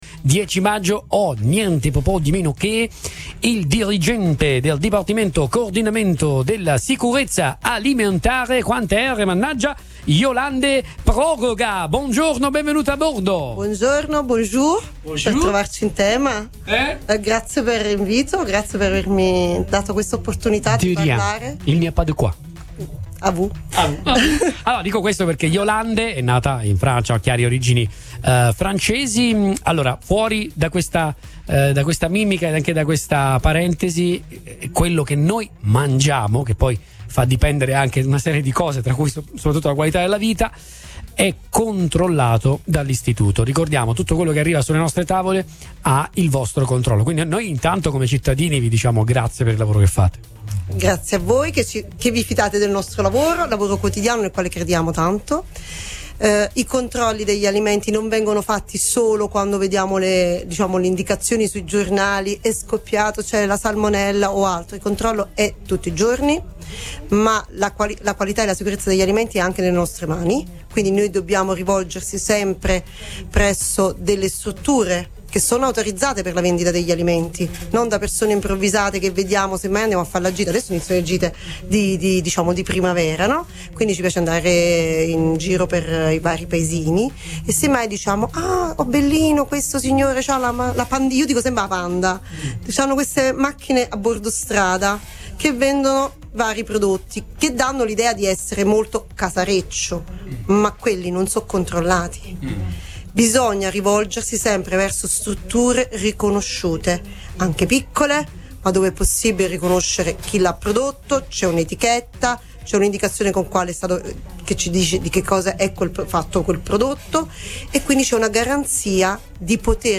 Fattorie Aperte, la sedicesima edizione è un grande successo
DI SEGUITO IL PODCAST DELLE INTERVISTE